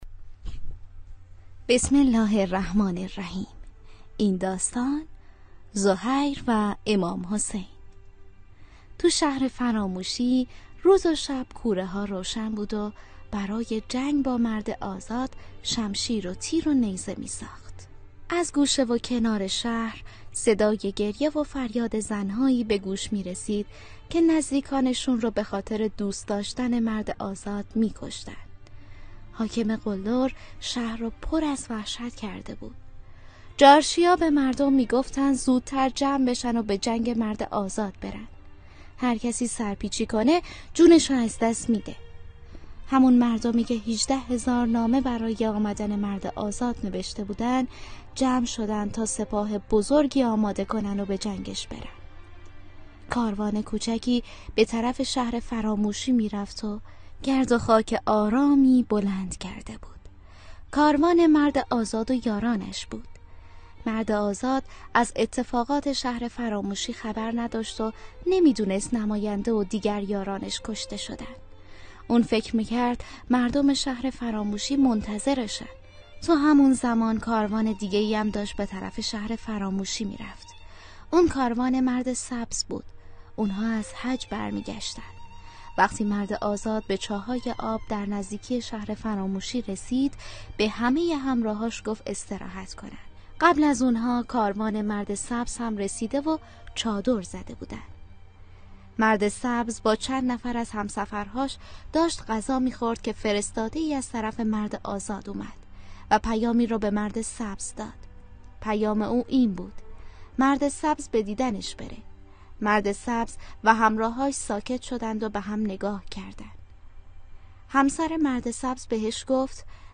داستان صوتی شهر فراموشی